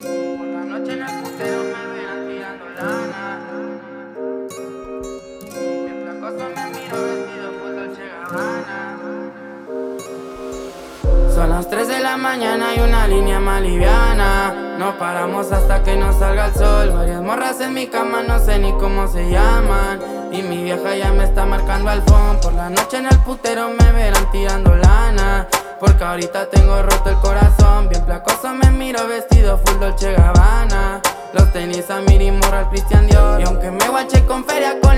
Скачать припев
Música Mexicana Latin